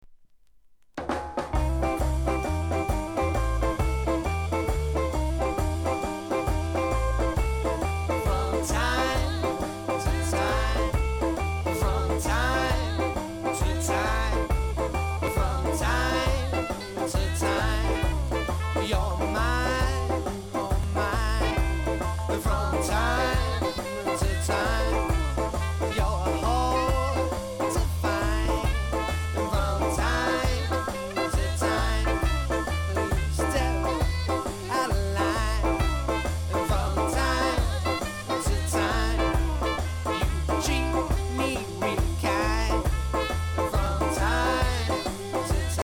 N.Y. SKA BAND!!